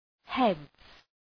Shkrimi fonetik {hedz}